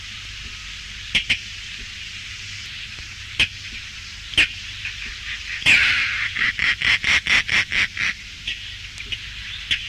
Rollier d'Europe
Coracias garrulus